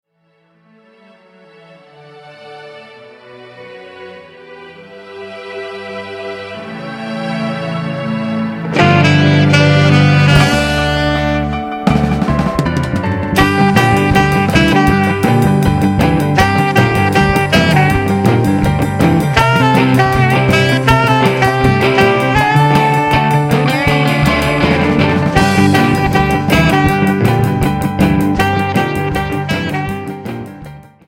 Sample from the Rehearsal CD